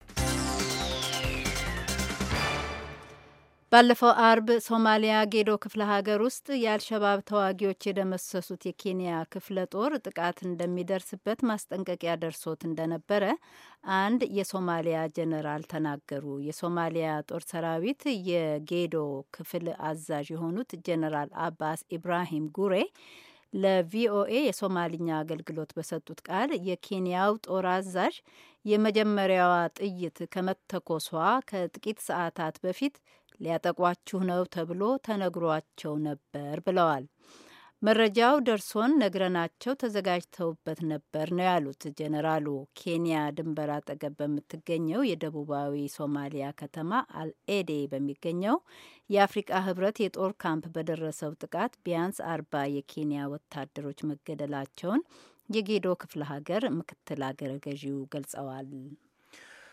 ኬንያ ድንበር አጠገብ በምትገኝ የደቡባዊ ሶማሊያ ከተማ ኤል አዴ በሚገኘው የአፍሪካ ህብረት የጦር ካምፕ በደረሰው ጥቃት ቢያንስ አርባ የኬንያ ወታደሮች መገደላቸውን የጌዶ ክፍለ ሀገር ምክትል አገረ ገዢ ገልጸዋል። የዜና ዘገባውን ከዚህ በታች ካለው የድምጽ ፋይል ያድምጡ።